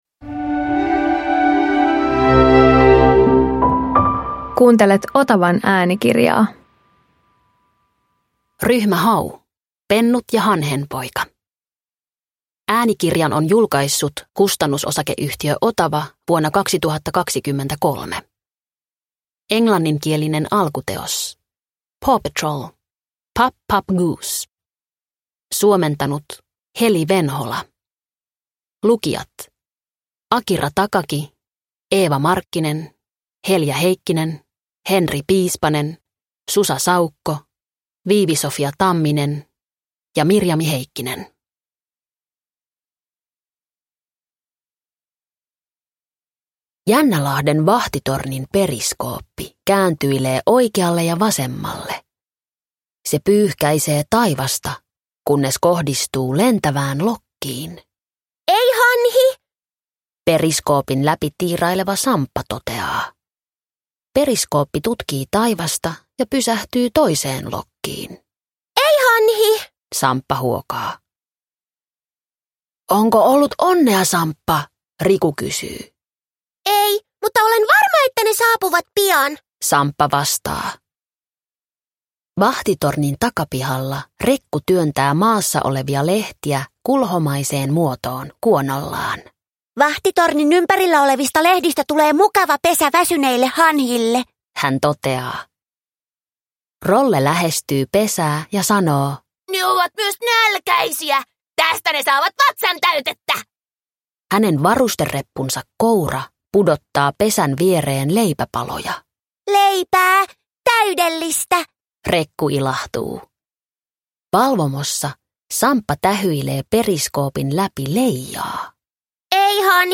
Ryhmä Hau Pennut ja hanhenpoikanen – Ljudbok – Laddas ner